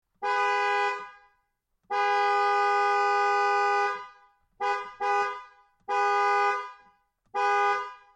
Loud Car Horn